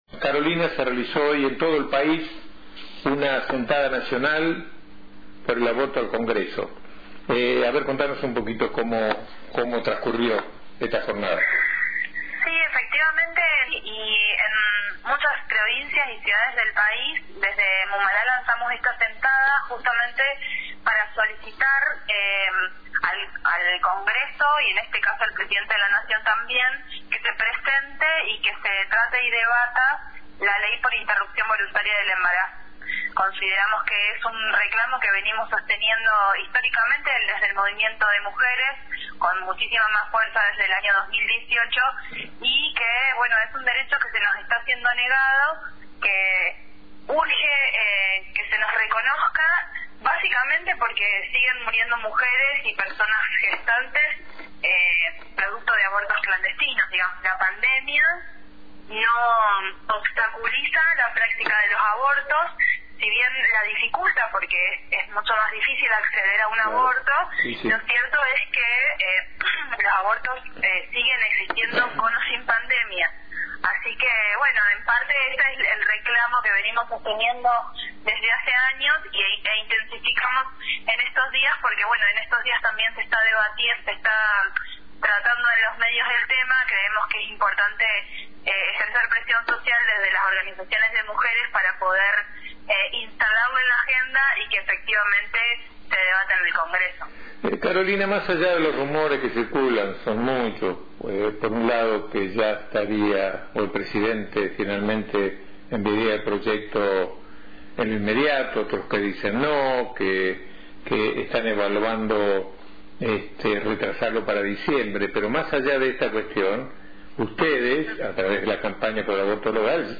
para escuchar la entrevista recordá pausar el reproductor de radio en vivo.